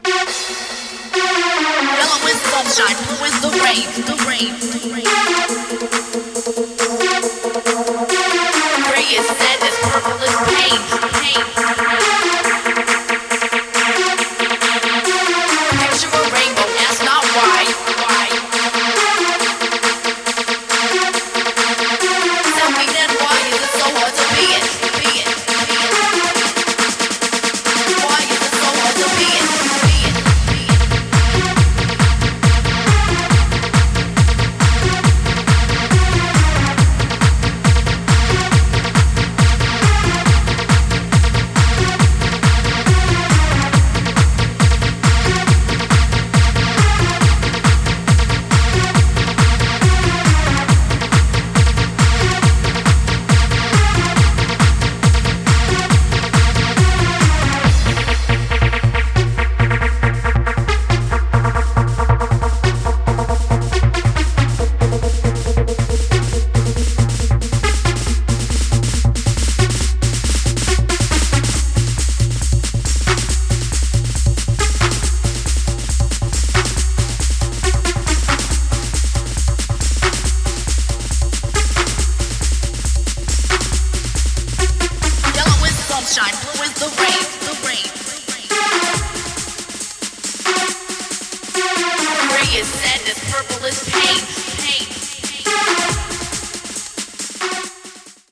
Electronic
TranceHard HouseHard TranceTechnoProgressive TranceJumpstyle